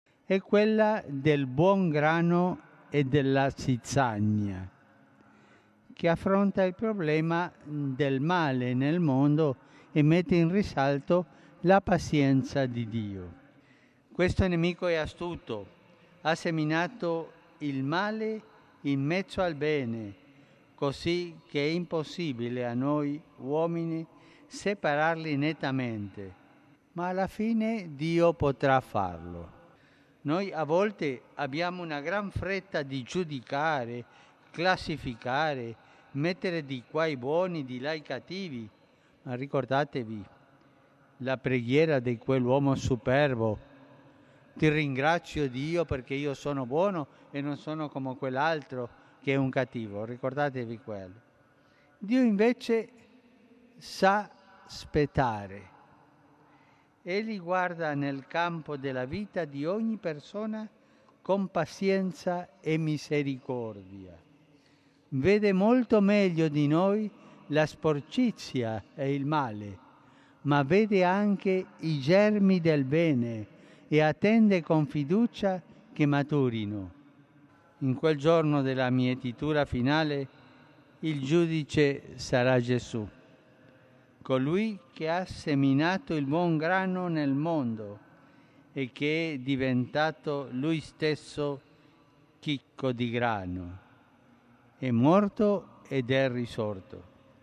(20.7) 34:n asteen helteestä huolimatta paavi Franciscusta odotti värikäs joukko Pietarintorilla.